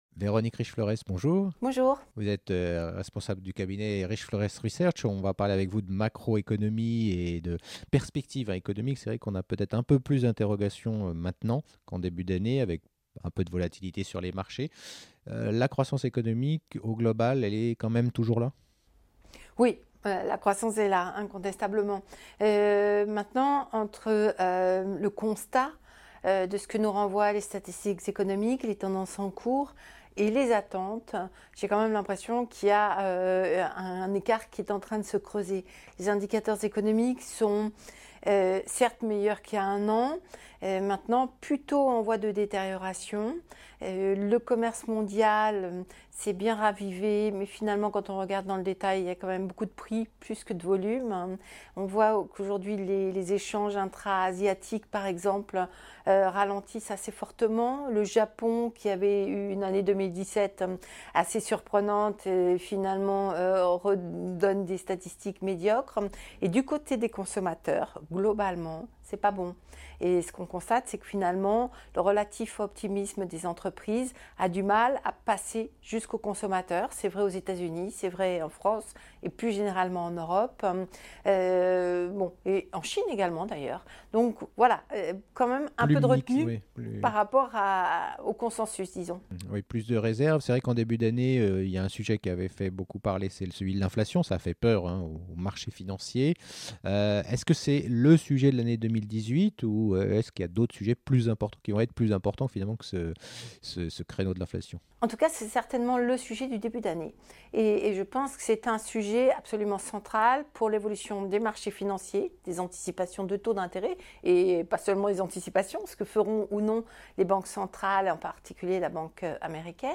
Actualités et perspectives économiques